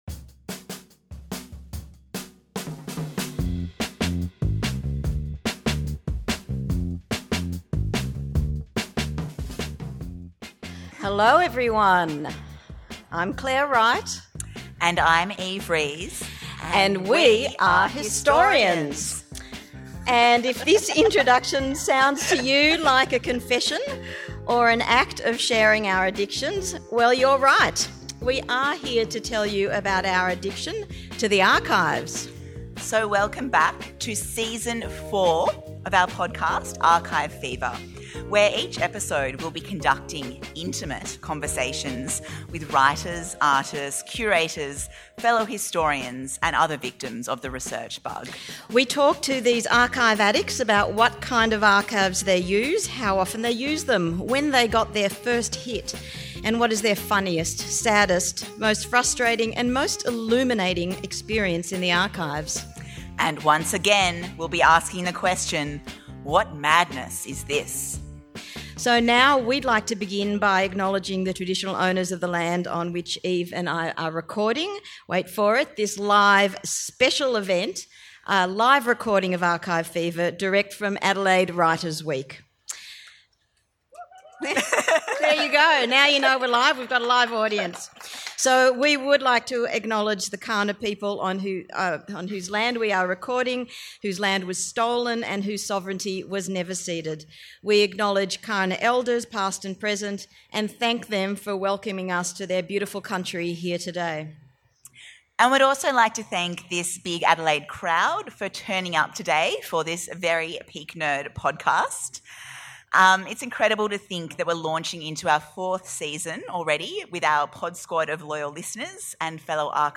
(Live at the Adelaide Writers' Festival)